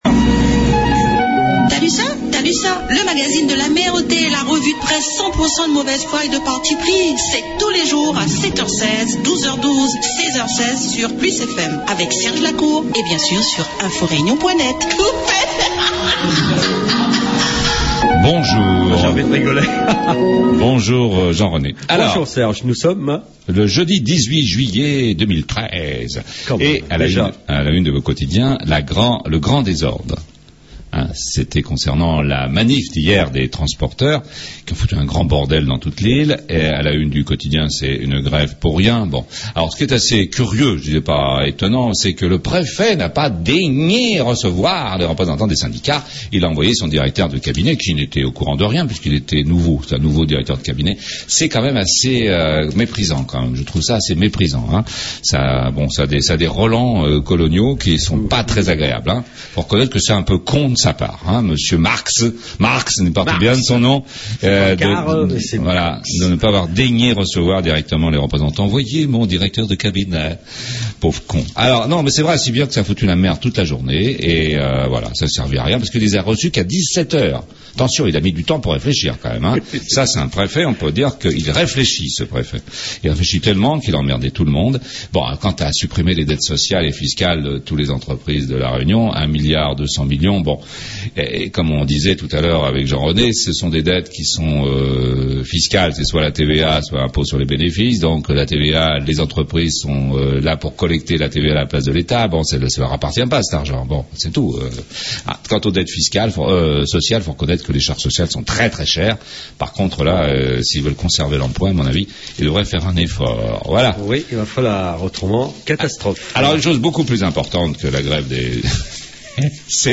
La revue de presse du jeudi 18 juillet 13. T'AS LU çA ?